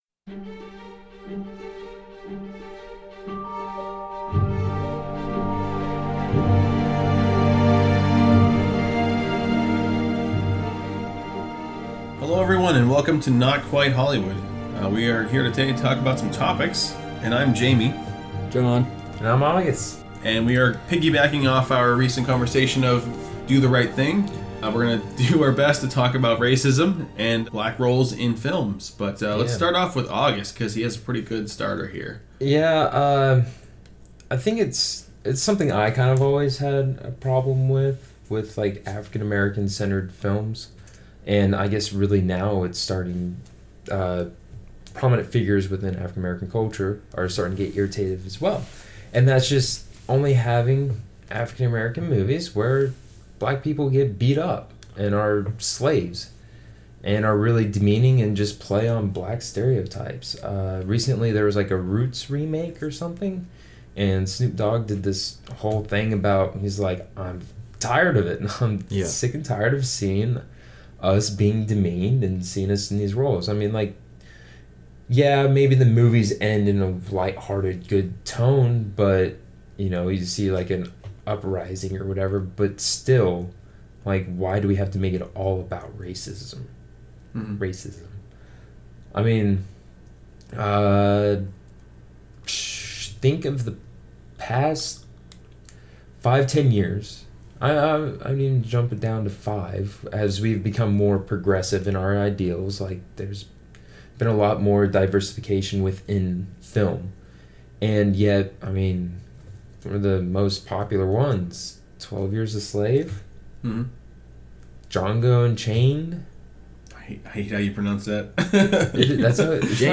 The guys at Not Quite Hollywood (three white men), try to get shut down already as the topic of race in films continues!